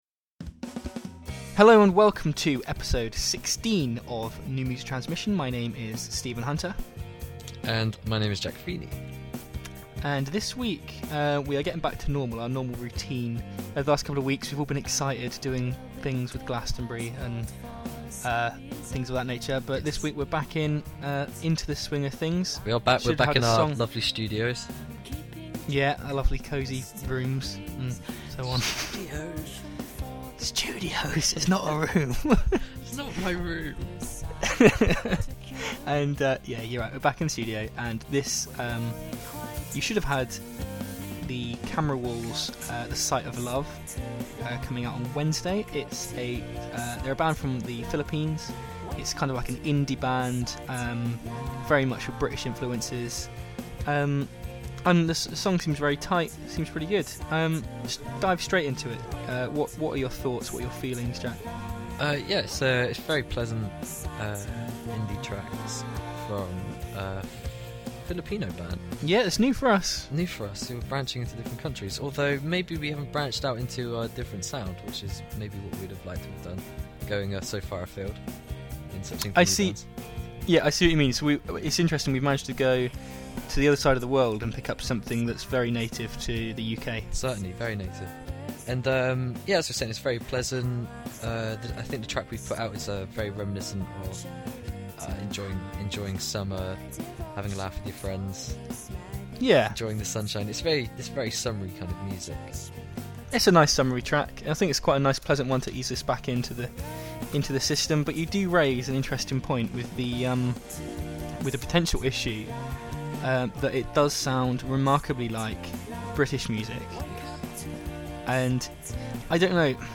Followed by a discussion feature I downloaded a week after which I decided to share with everyone by inserting the mp3 file. We felt honored with the nice commentaries injected with very constructive criticisms.